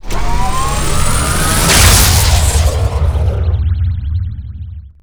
StarProbeLaunch.wav